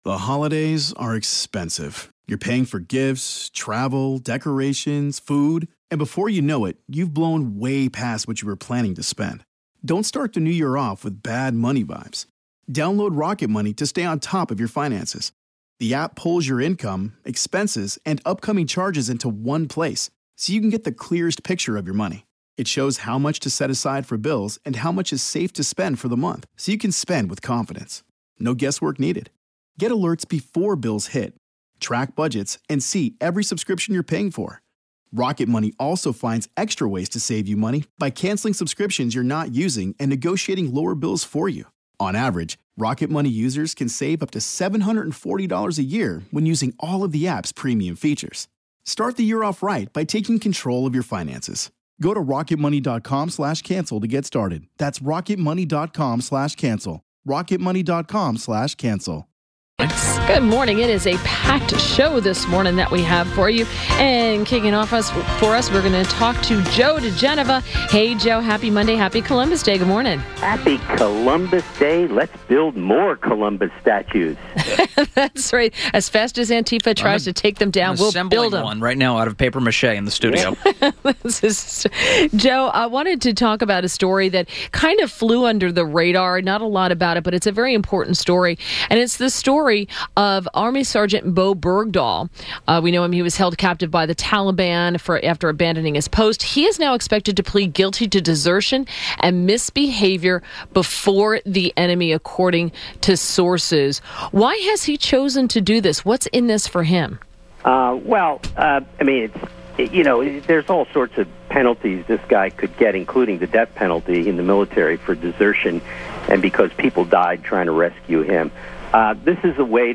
INTERVIEW – JOE DIGENOVA – legal analyst and U.S. Attorney to the District of Columbia